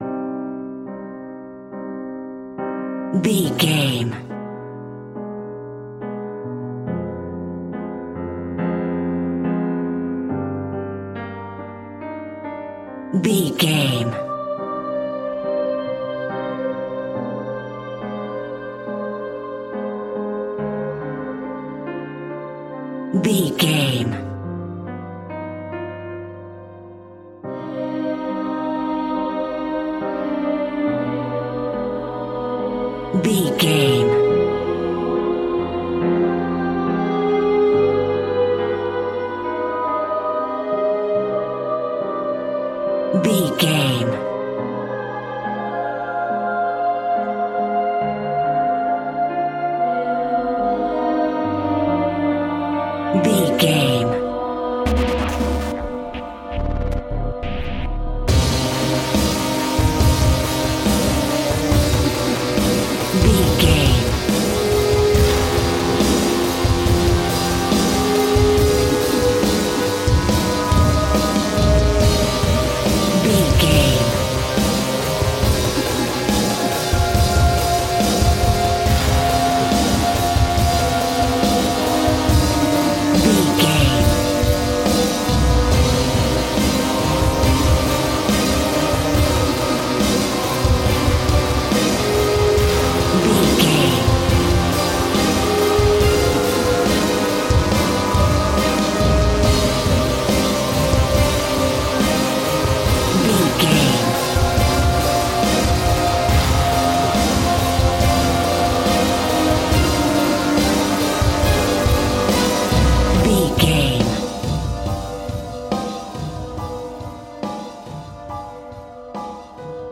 In-crescendo
Thriller
Aeolian/Minor
scary
tension
ominous
disturbing
haunting
eerie
strings
brass
percussion
horror
wood wind